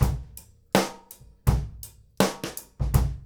GROOVE 9 08R.wav